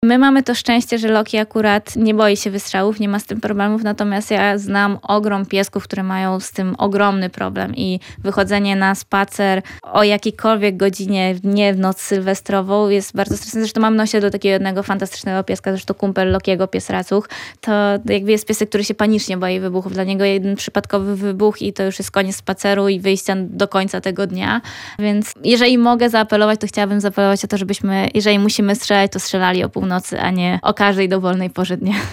Odgłos wybuchających petard czy fajerwerków bywa jednak bardzo stresujący dla zwierząt. W związku z tym szczególny apel do miłośników pirotechniki ma złota medalistka igrzysk olimpijskich Aleksandra Mirosław, która jest właścicielką psa Lokiego.